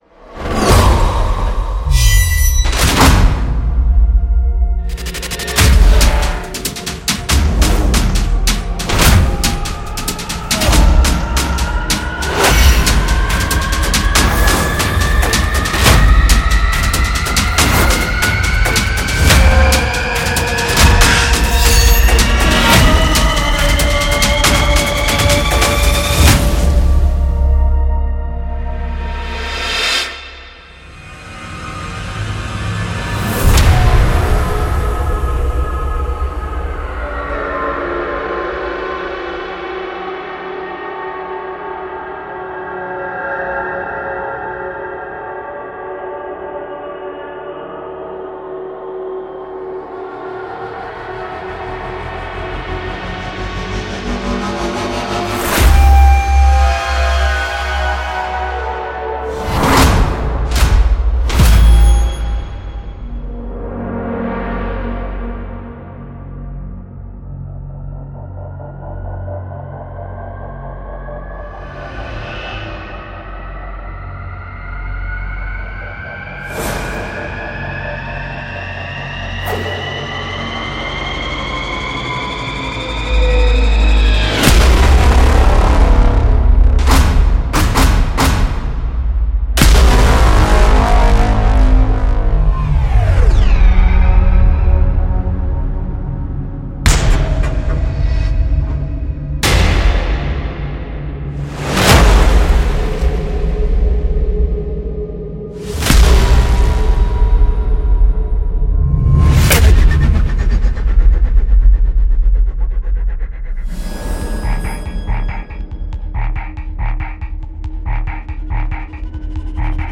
800+电影声音：科幻诸神战斗打击，强大的冲击力，高昂的号角，黑暗的战斗bra氛围音效合集 Cinetools – Titanomachy（4.54G）
Cinetools展示了“ Titanomachy”，具有+800多种先进的电影声音，充满张力建立的飞旋声，打击，强大的冲击力，高昂的号角，黑暗的战斗bra，无人驾驶飞机和雄伟的战场氛围，为您的预告片，剪辑场景，游戏和电影增添趣味-这拥有您为战斗场景和激烈的战争游戏评分所需的一切。
“ Titanomachy”中包含的各种声音包括使您的作品取得胜利的一切；扭曲的巨型号角，调制的黄铜，剧烈的合成线，合成和有序的脉冲，史诗般的战鼓和无人机，战斗打击，轰炸，轰炸，撞击，爆炸，战斗氛围和潮湿的气氛，悬疑的上升，令人恐惧的毒刺，史诗般的标记，动态的扫荡所有这些声音都是专门为电影爱好者提供的，用于创建高清电影作品的真正多样化的音色，也非常适合需要电影标准声音的许多其他情况。
每种声音最初都是以24位/ 96kHz录制的，以确保满足当今电影制作需求的最高质量。